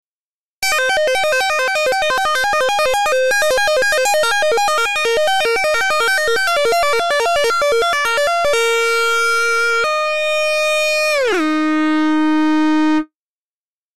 For all examples, the asymmetric distortion model was used, as it produced the best distorted tone.
"Tapping" in Monophonic Model
Quick arpeggios are played to simulate "tapping" high on the neck, a technique used by artists such as Eddie Van Halen.
tapping.mp3